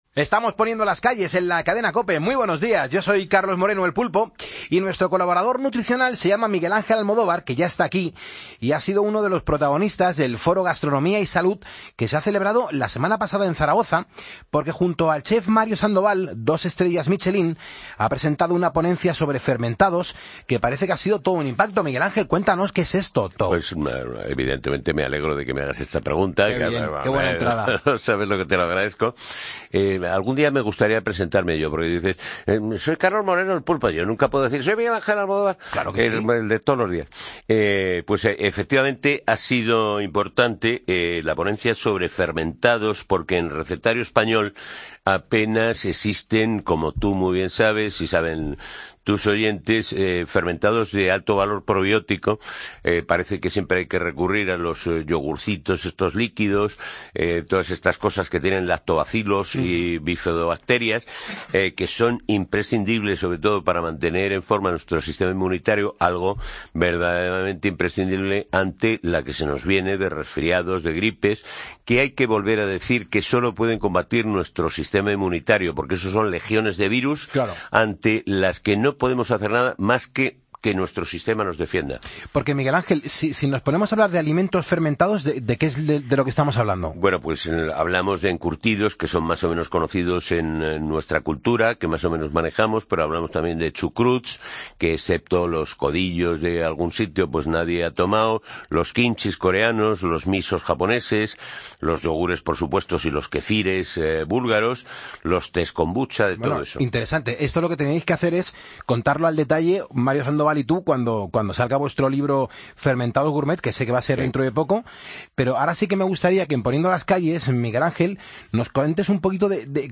Foro Gastronomía y Salud: Ponencia de Mario Sandoval sobre fermentados